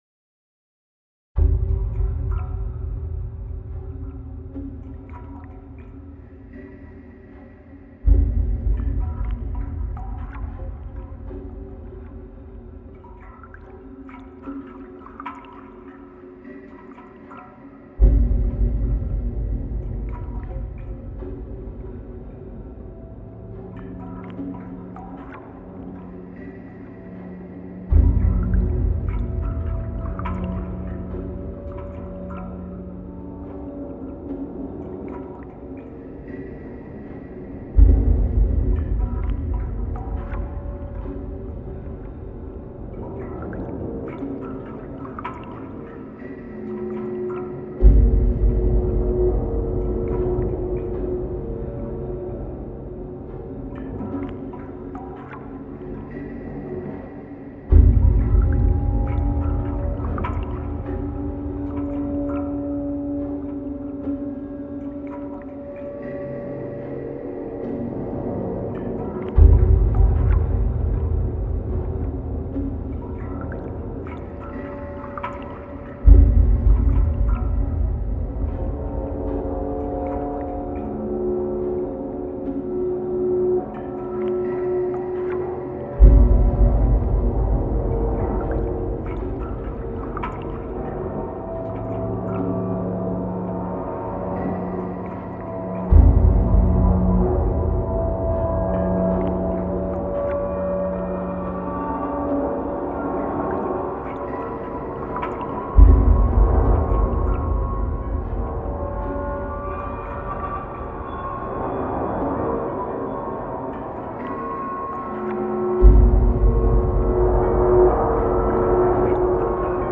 Ambient Эмбиент Музыка ambient